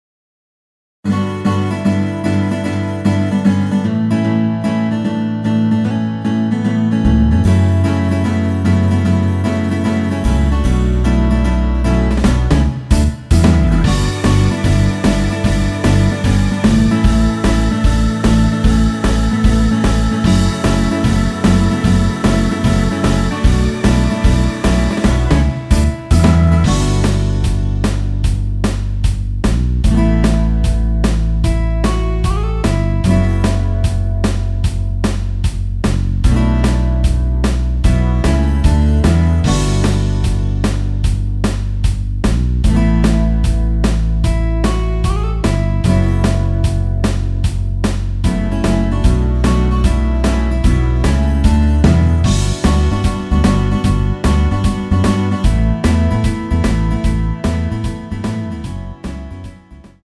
엔딩이 페이드 아웃이라 라이브 하시기 좋게 엔딩을 만들어 놓았습니다.
Bm
앞부분30초, 뒷부분30초씩 편집해서 올려 드리고 있습니다.
중간에 음이 끈어지고 다시 나오는 이유는